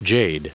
Prononciation du mot jade en anglais (fichier audio)